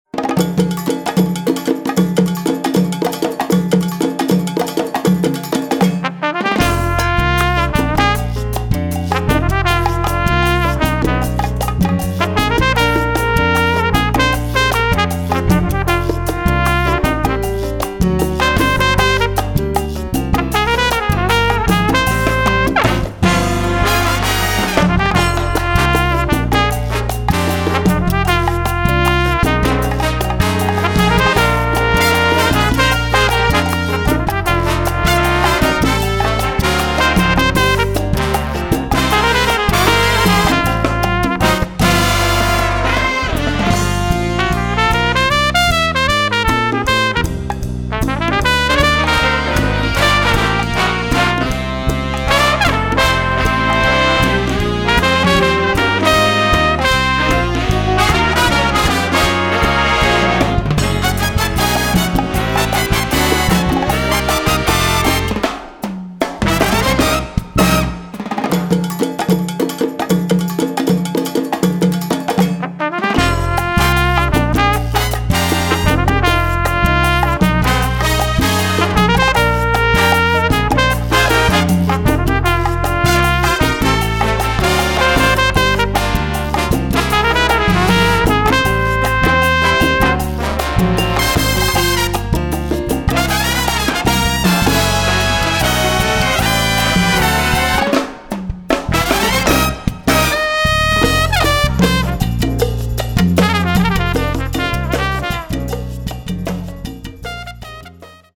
Category: big band
Style: cha cha
Solos: open
Instrumentation: big band (4-4-5, guitar, rhythm (4)